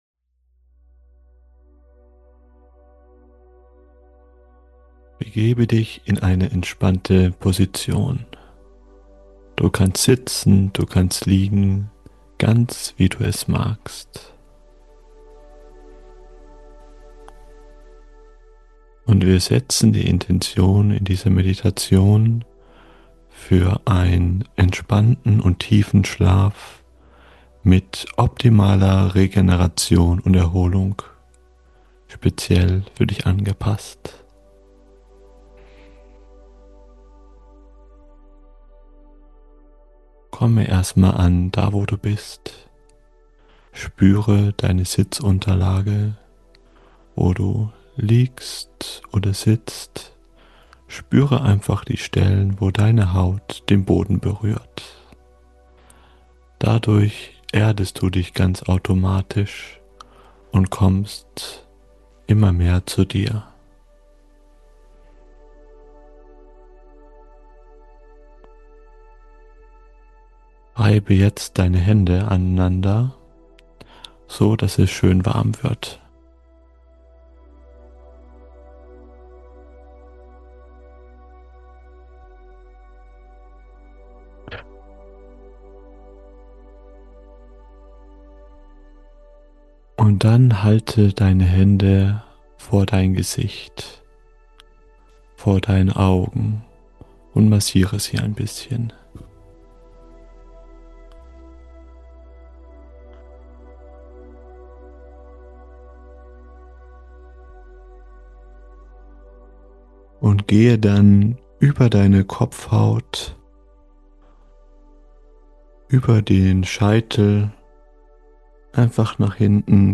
#133 Meditationen zum Einschlafen ~ BusinessHippie.